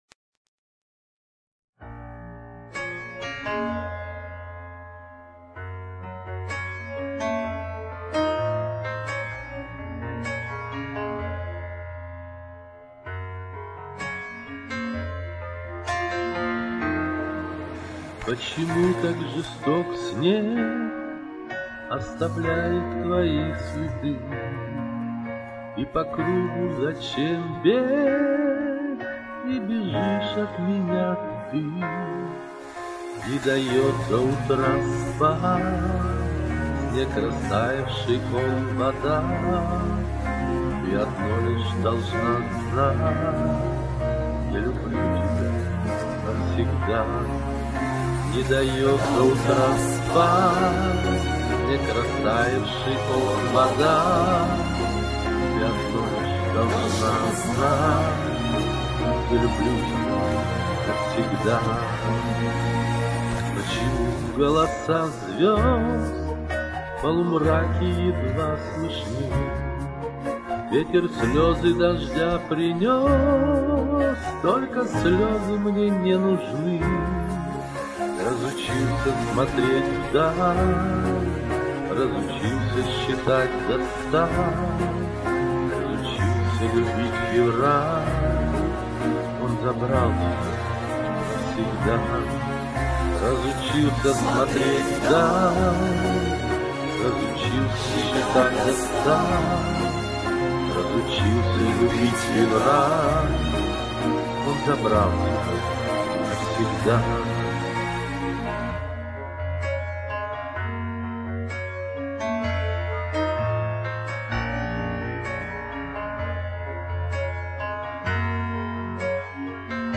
песня звучит по-домашнему.
скорее это мелодекламация, а не пение